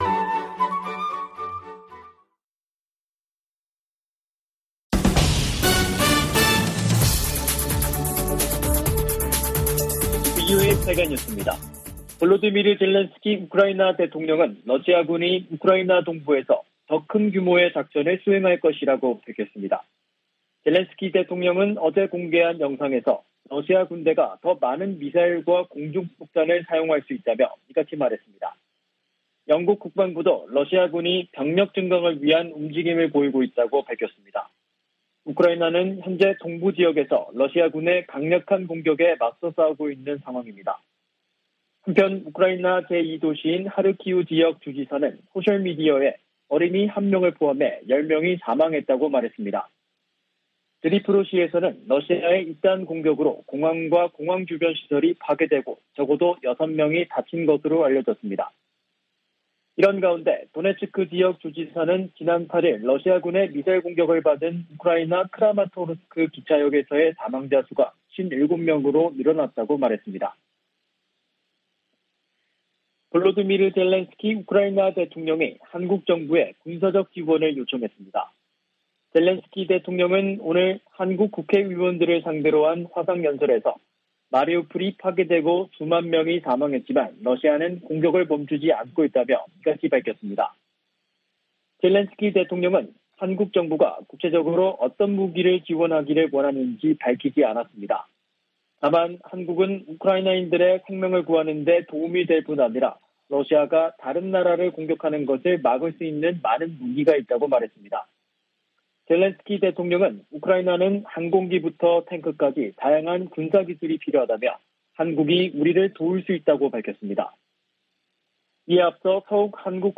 VOA 한국어 간판 뉴스 프로그램 '뉴스 투데이', 2022년 4월 11일 2부 방송입니다. 미 국무부는 북한 비핵화 목표에 정책 변화가 없다고 밝히고, 대화에 나오라고 북한에 촉구했습니다. 미 국방부는 북한의 위협을 잘 알고 있다며 동맹인 한국과 훈련과 준비태세를 조정하고 있다고 밝혔습니다. 북한이 추가 핵실험을 감행할 경우 핵탄두 소형화를 위한 실험일 수 있다는 전문가들의 지적이 이어지고 있습니다.